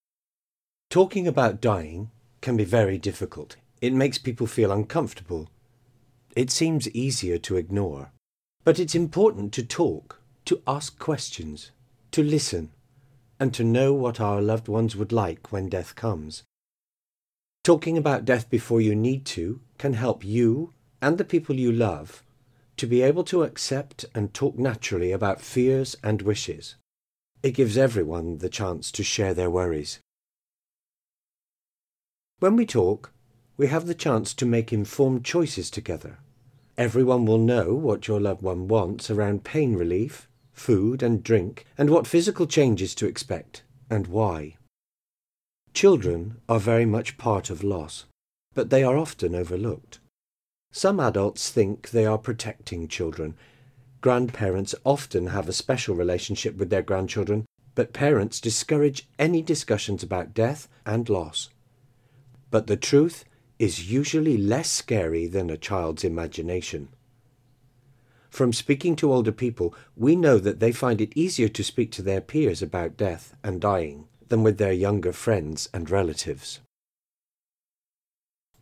General UK & RP
I have my own home studio and can record with a regionally neutral UK accent or if needed a Heightened RP.